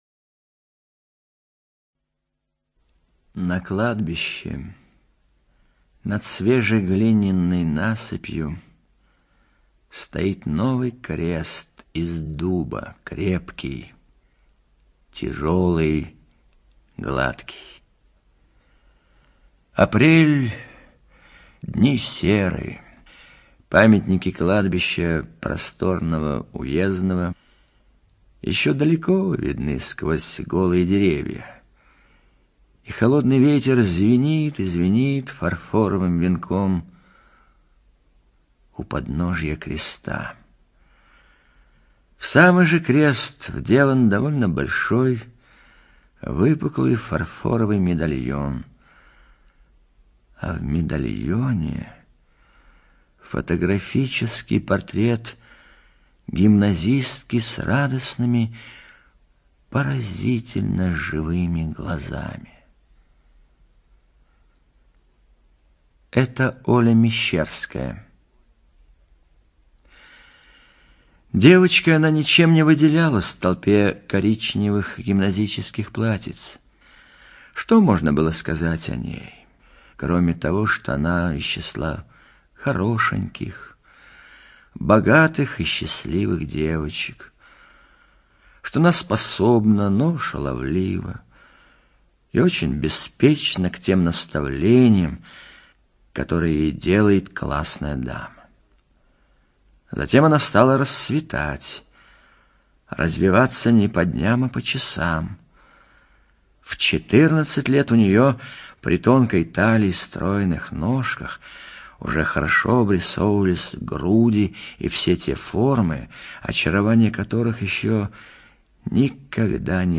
ЧитаетМарцевич Э.